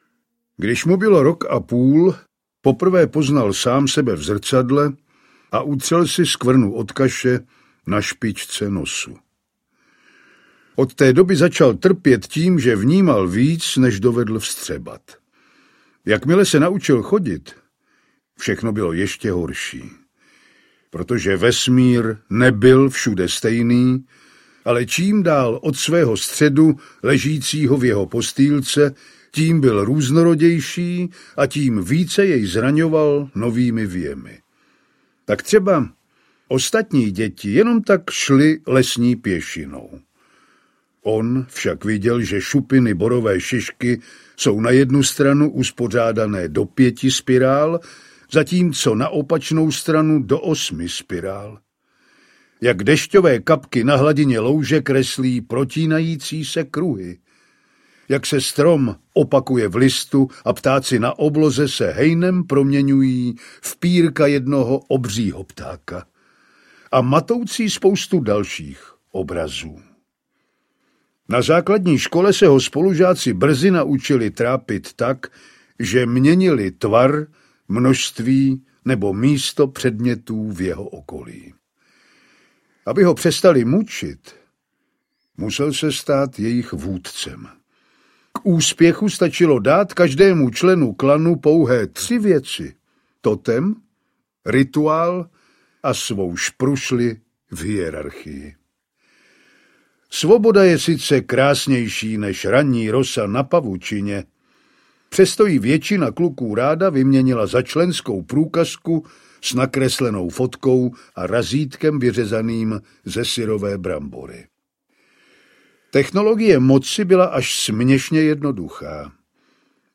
Nulorožec audiokniha
Ukázka z knihy